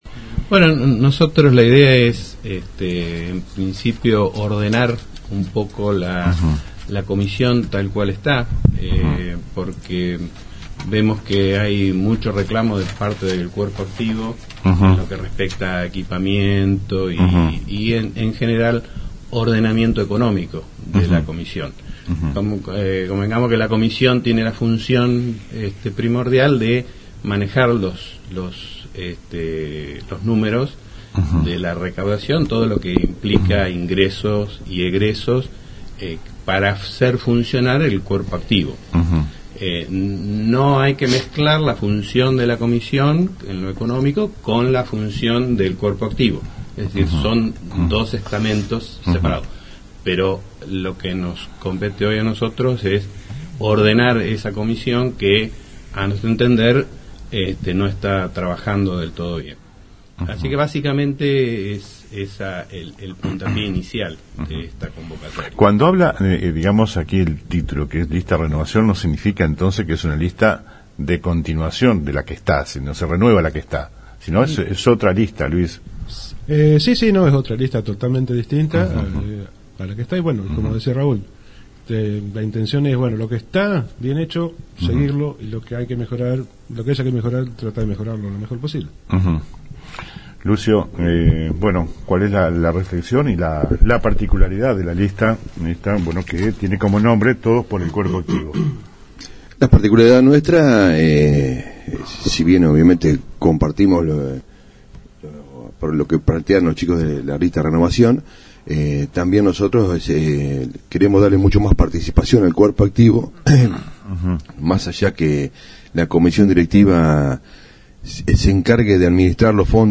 hablamos este martes en el piso de la radio con los integrantes de las dos listas